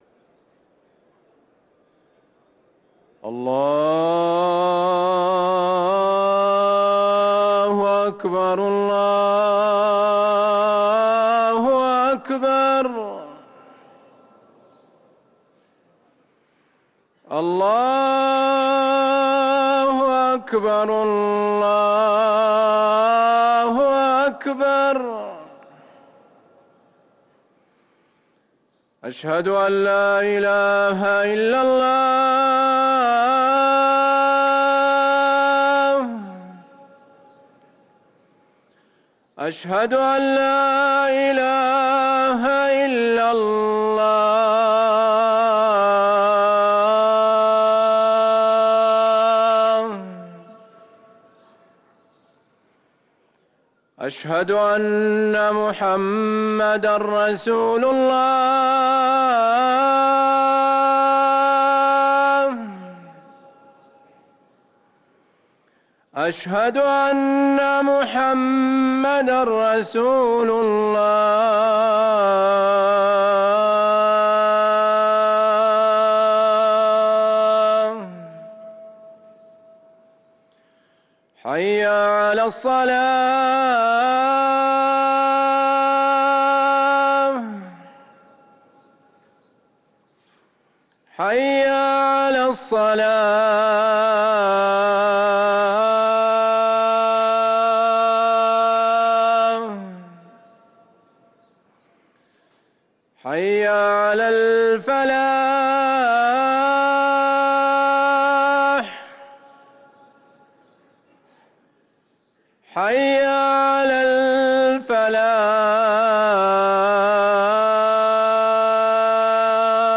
أذان الفجر